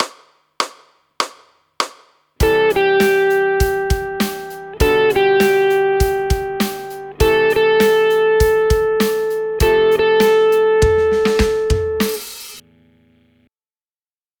We have chosen the I-V-vi-IV progression, which is one of the most popular chord progressions of all time.
The next set of examples plays the scale tone followed by the release, in this case the closest chord tone.
Sixth Resolving to Chord Tone (ex. a)